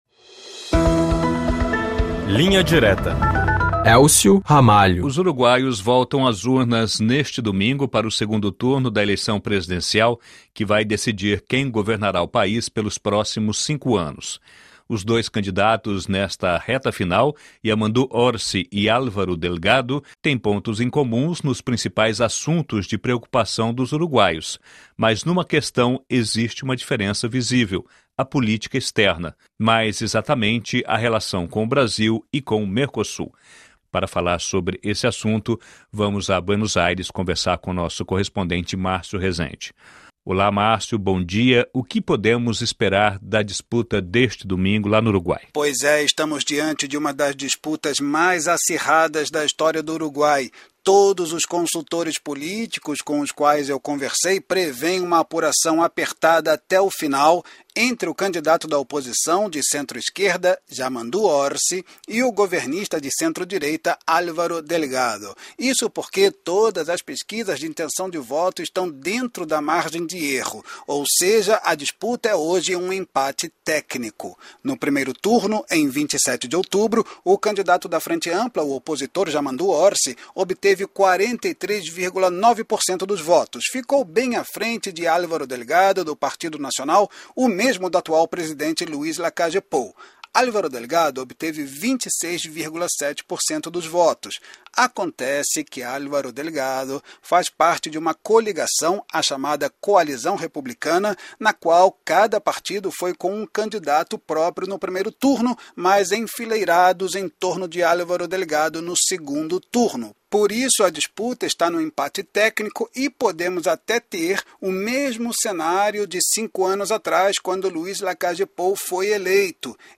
Bate-papo com os correspondentes da RFI Brasil pelo mundo para analisar, com uma abordagem mais profunda, os principais assuntos da atualidade.